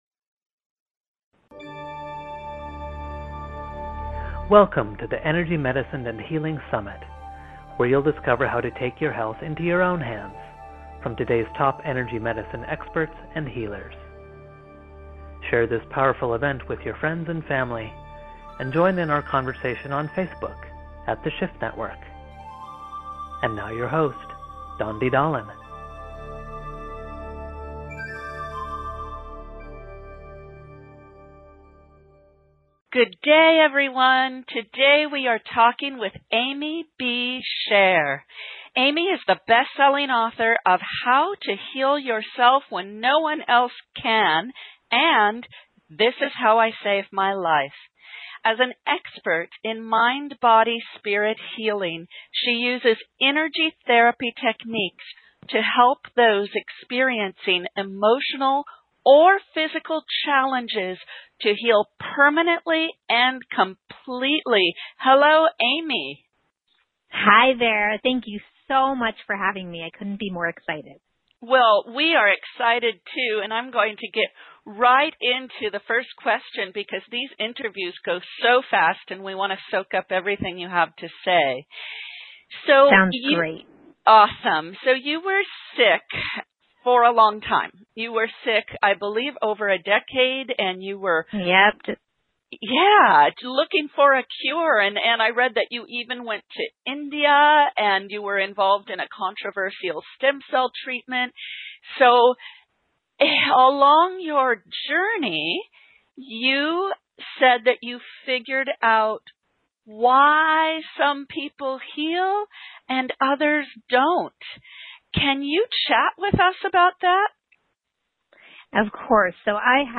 Listen to my interview for the Energy Medicine & Healing Summit.